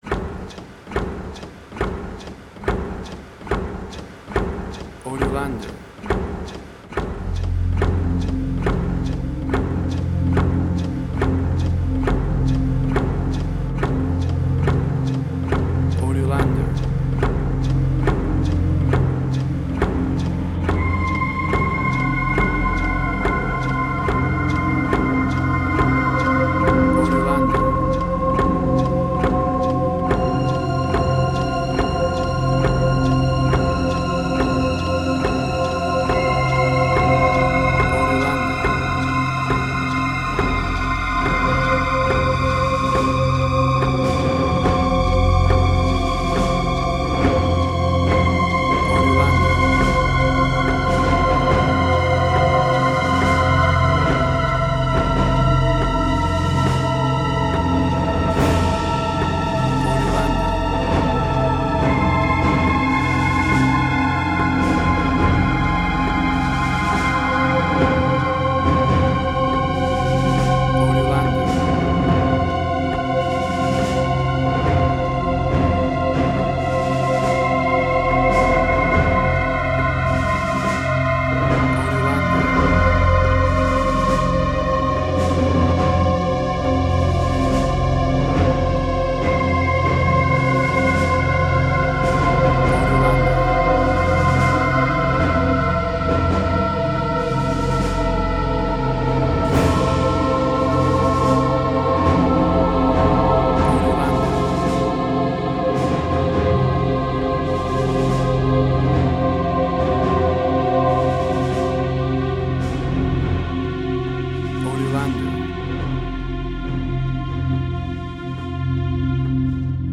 Dissonance Similar The Hurt Locker Fear.
WAV Sample Rate: 16-Bit stereo, 44.1 kHz
Tempo (BPM): 70